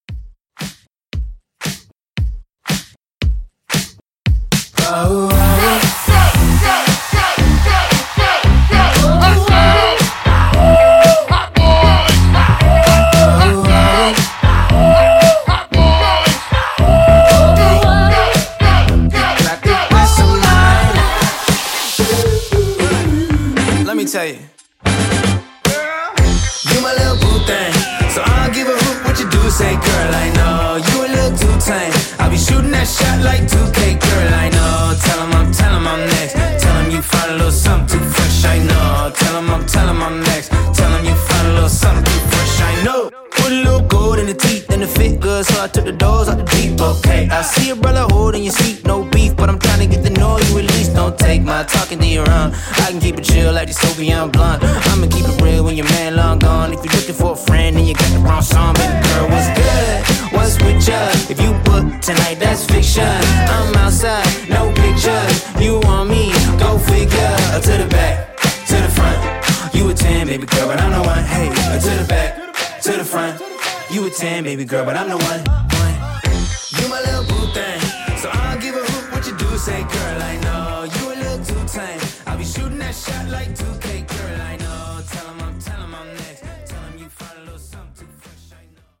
Genre: HIPHOP Version: Clean BPM: 115 Time